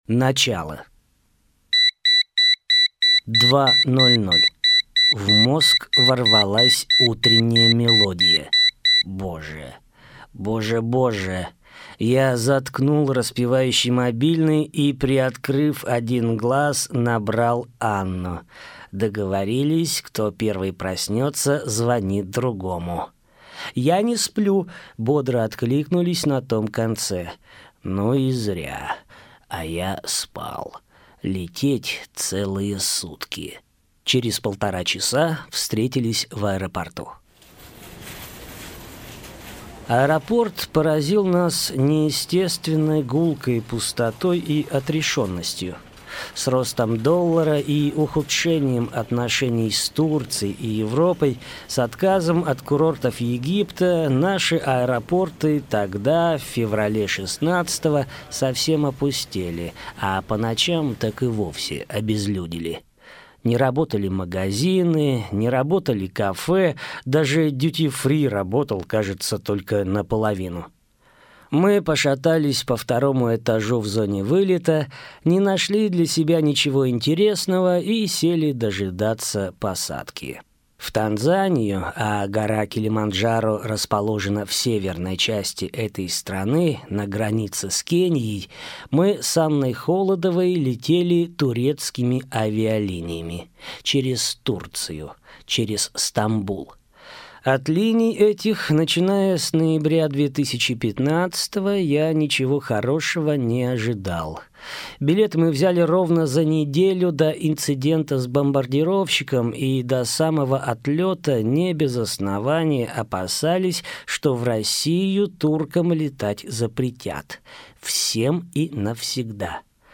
Аудиокнига Килиманджаро. С женщиной в горы. В горы после пятидесяти – 3 | Библиотека аудиокниг